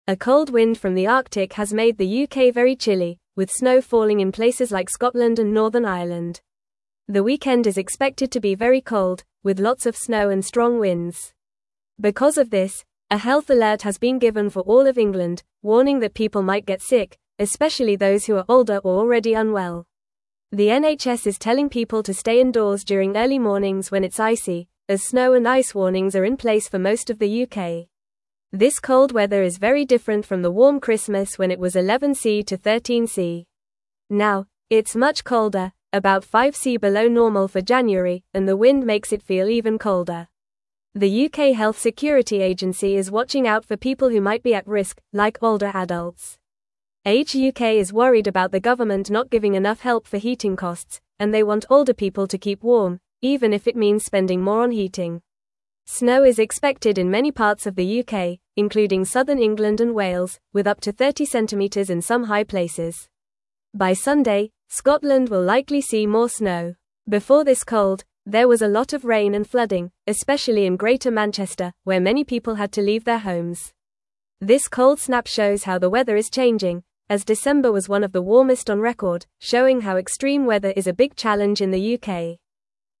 Fast
English-Newsroom-Lower-Intermediate-FAST-Reading-Big-Cold-Wind-Brings-Snow-to-the-UK.mp3